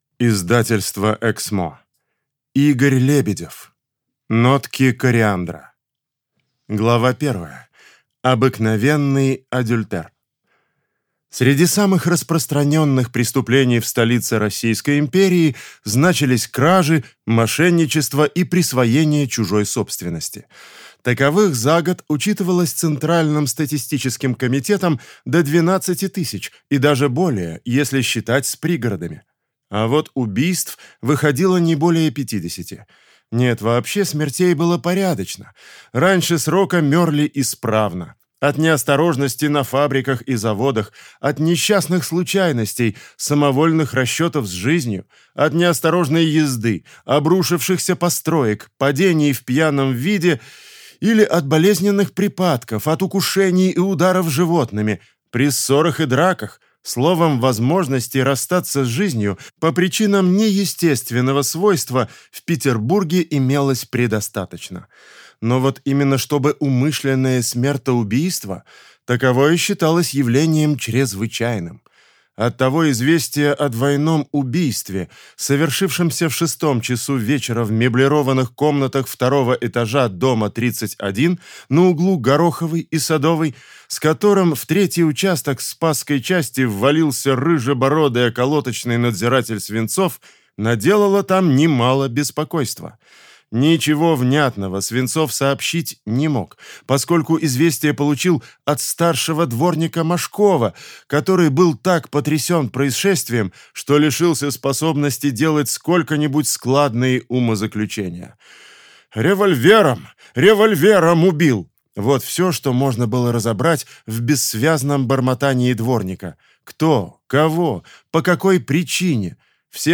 Аудиокнига Нотки кориандра | Библиотека аудиокниг
Прослушать и бесплатно скачать фрагмент аудиокниги